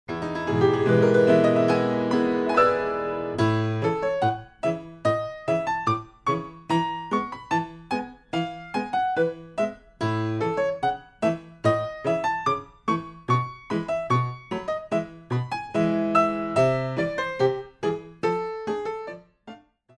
Classical Arrangements for Pre Ballet Classes